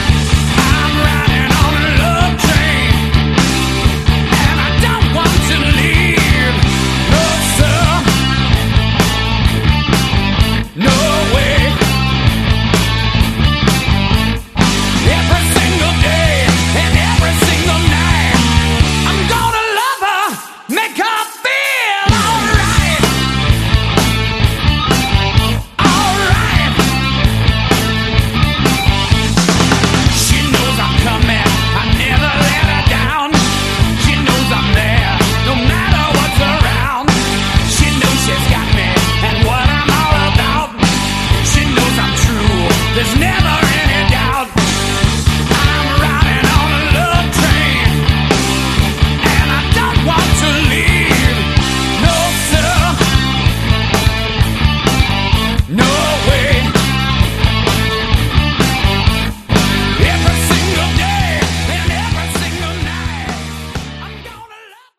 Category: Bluesy Hard Rock
guitar
vocals
drums
bass